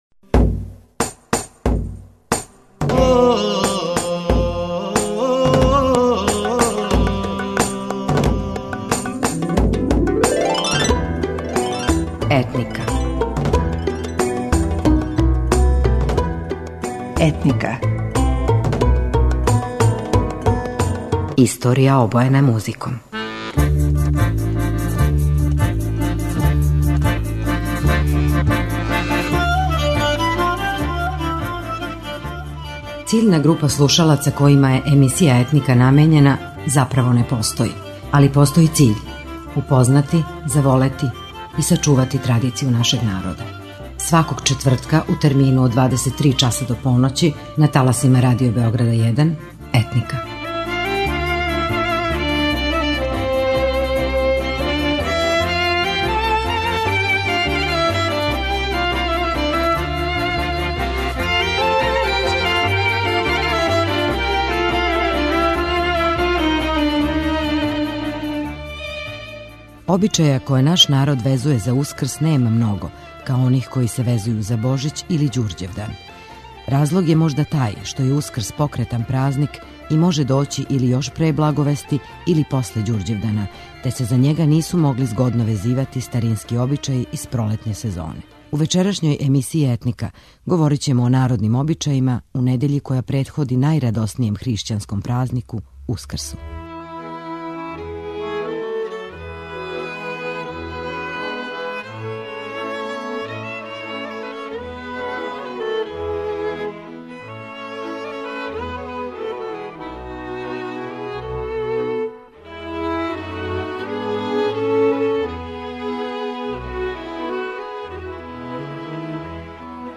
Уз лепу традиционалну музику, подсетићамо вас на обичаје који су везани за један од највећих хришћанских празника, Ускрс.